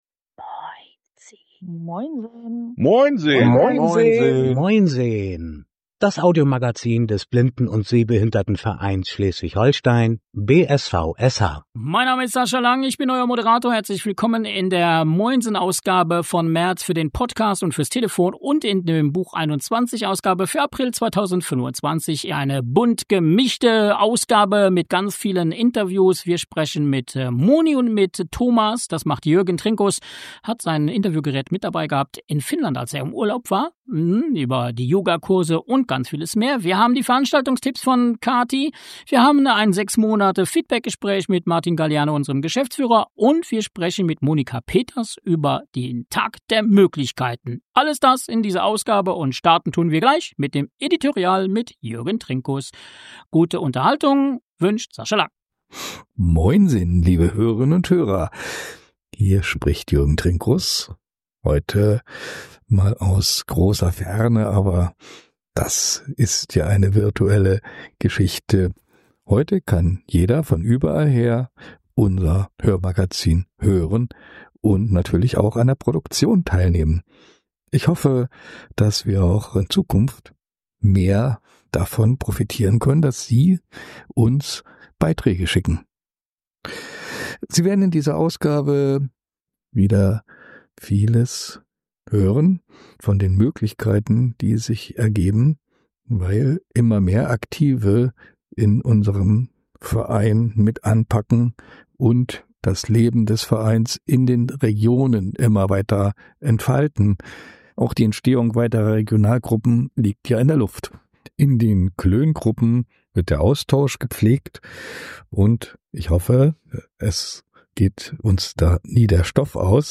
Herzlich willkommen zur März-Ausgabe unseres Audiomagazins „Moin sehen“, dem Podcast des Blinden- und Sehbehindertenvereins Schleswig-Holstein (BSVSH).